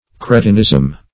Cretinism \Cre"tin*ism\ (kr[=e]"t[i^]n*[i^]z'm), n. [F.